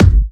VEC3 Bassdrums Trance 19.wav